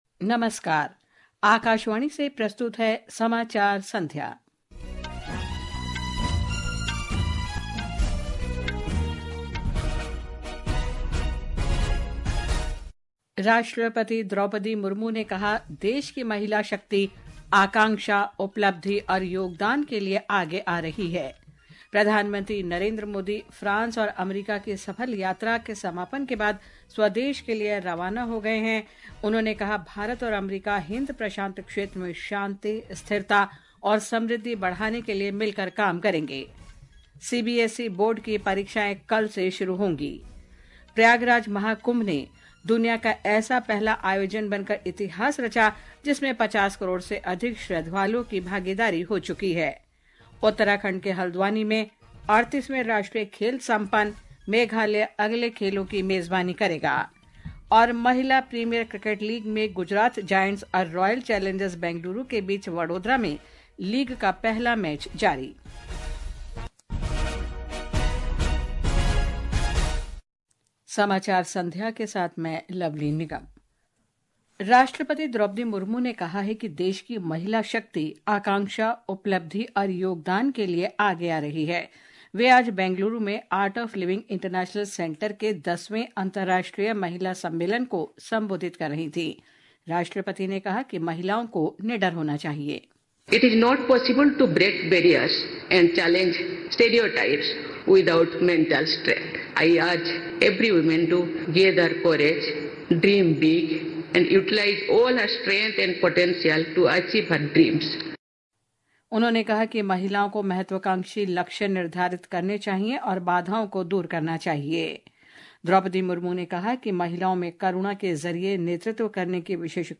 জাতীয় বুলেটিন | ডিডি নিউজ অন এয়াৰ